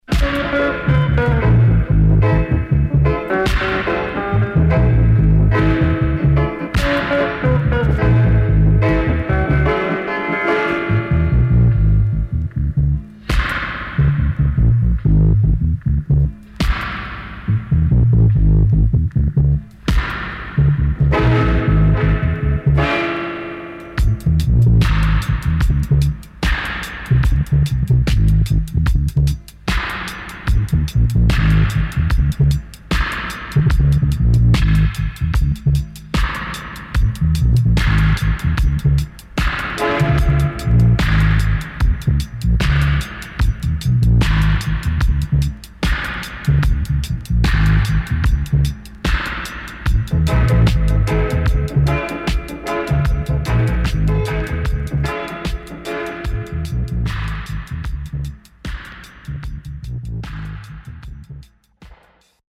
Killer Roots Vocal & Dubwise